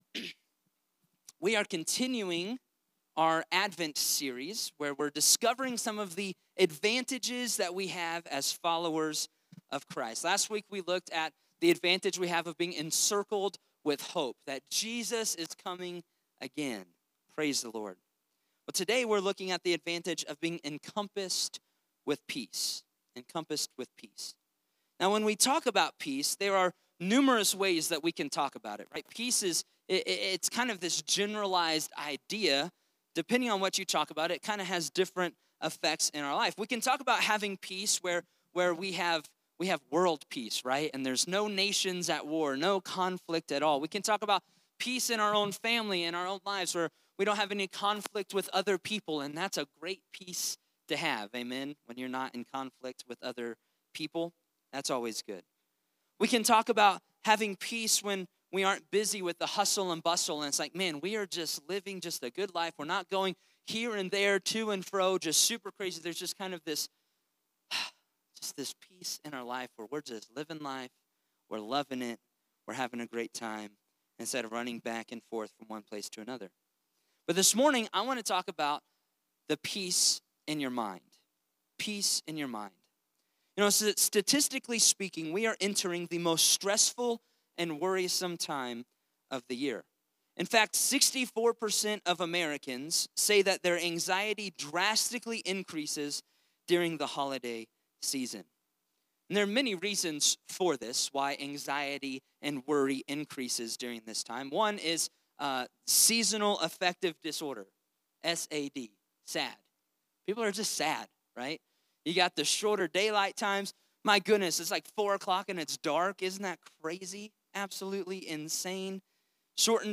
Sermons | Mountain View Assembly